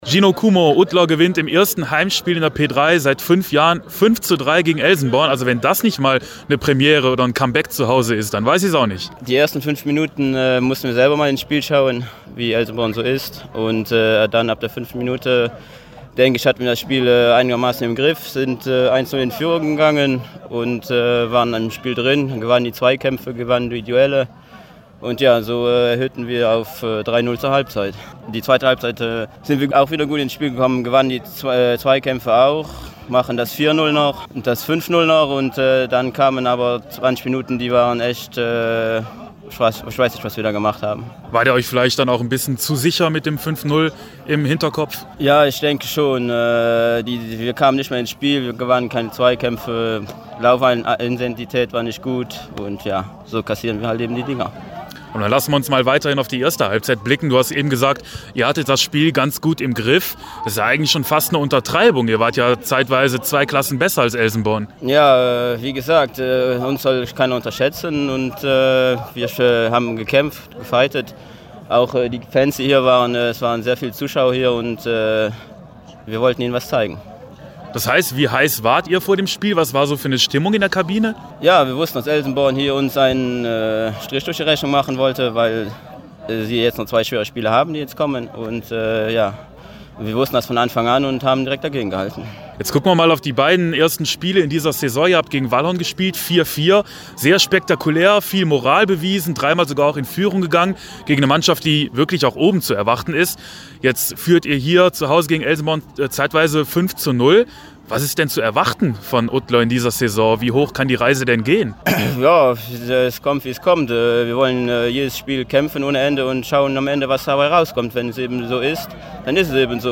nach dem Spiel.